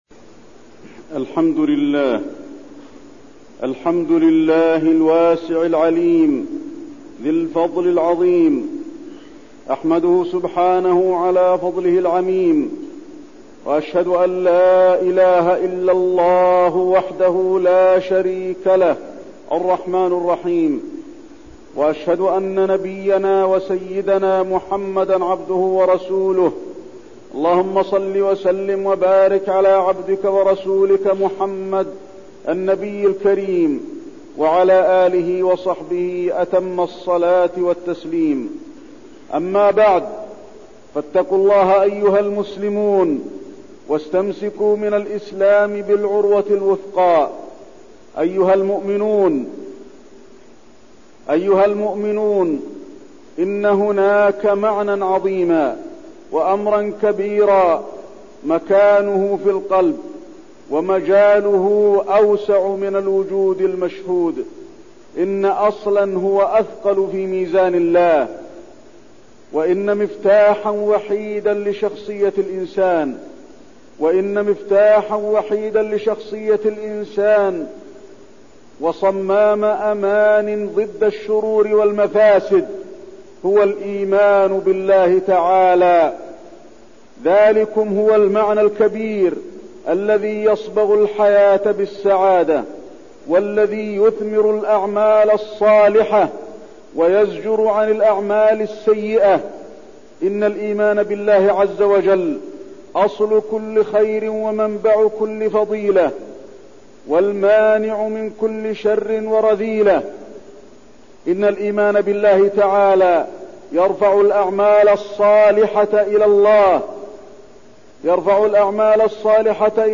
تاريخ النشر ٢٢ ربيع الثاني ١٤١١ هـ المكان: المسجد النبوي الشيخ: فضيلة الشيخ د. علي بن عبدالرحمن الحذيفي فضيلة الشيخ د. علي بن عبدالرحمن الحذيفي الإيمان بالله